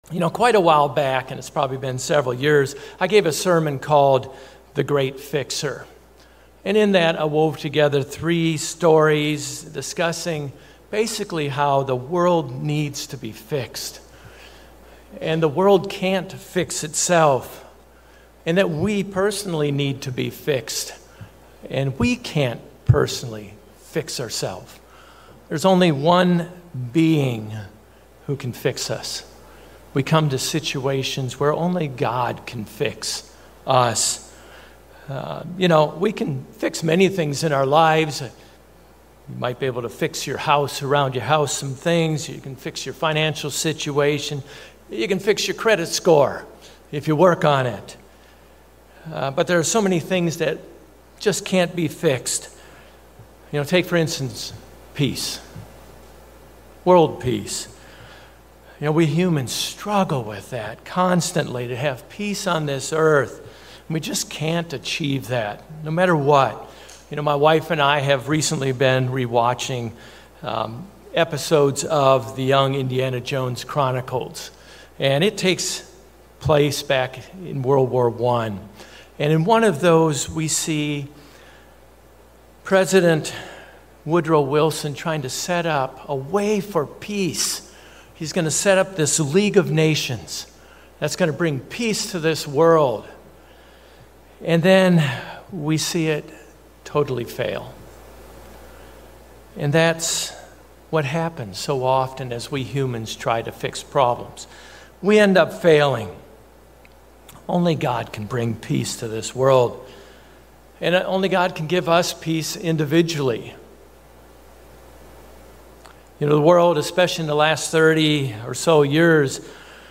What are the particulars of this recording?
Given in Orlando, FL